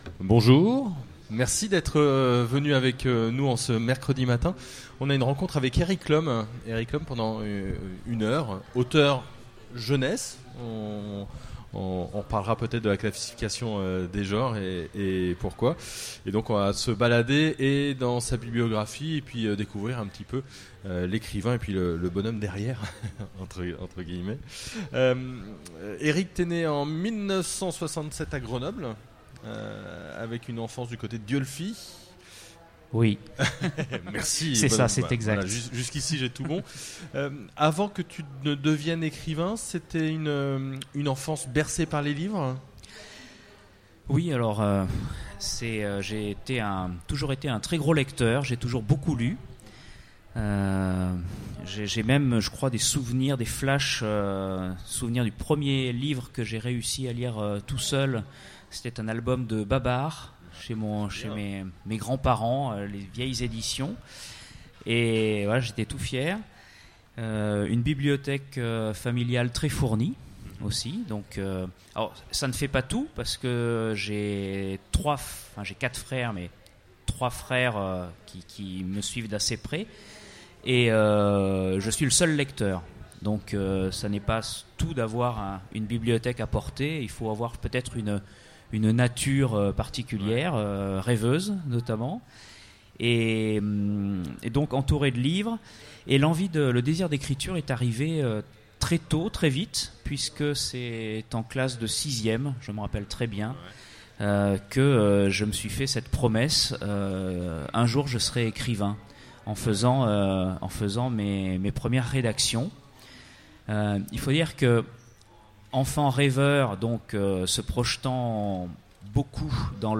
Utopiales 2016 : Rencontre avec Erik L'Homme
- le 31/10/2017 Partager Commenter Utopiales 2016 : Rencontre avec Erik L'Homme Télécharger le MP3 à lire aussi Erik L'Homme Genres / Mots-clés Rencontre avec un auteur Conférence Partager cet article